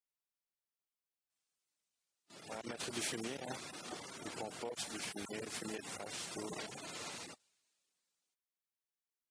prononciation Du fumier